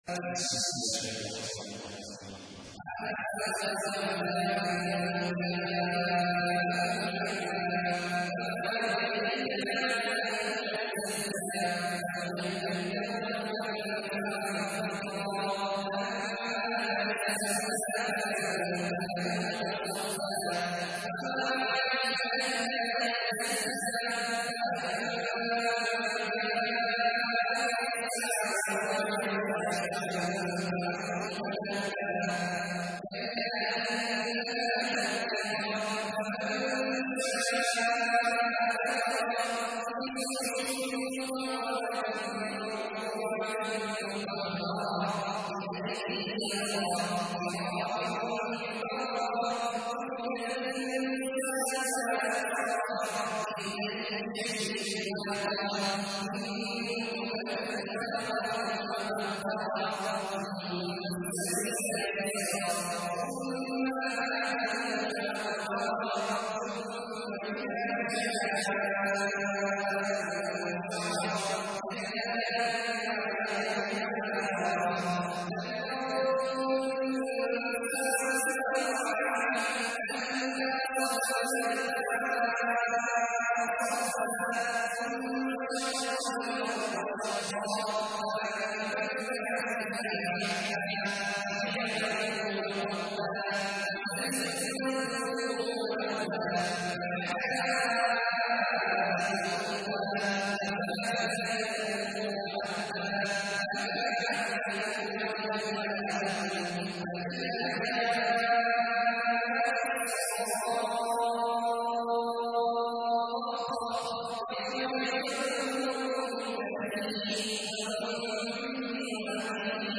تحميل : 80. سورة عبس / القارئ عبد الله عواد الجهني / القرآن الكريم / موقع يا حسين